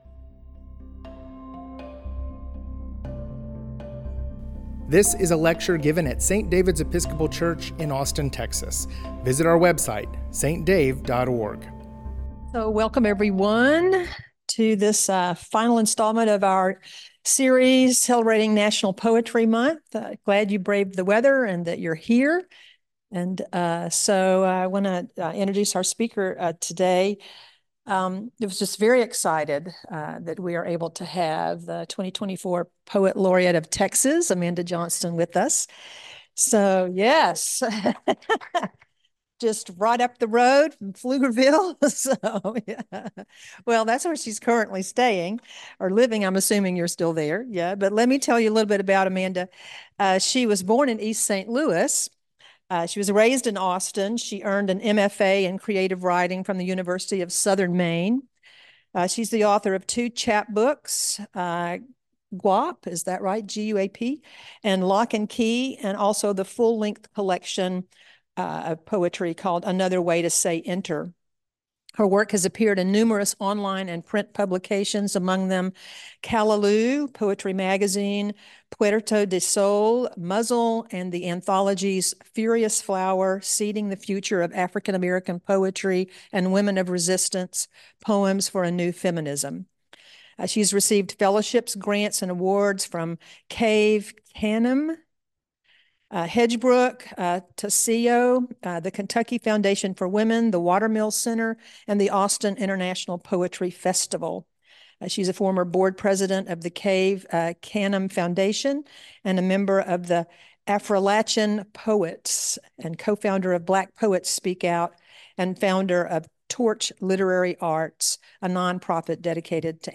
Amanda Johnston, the 2024 Texas Poet Laureate, completed our National Poetry Month lecture series by reading some of her favorite poets and her own poetry. She invited us to reflect on poetry's power to engage us in ways that challenge us, provoke us to action, and help us access our deeper wisdom as individuals and as a community.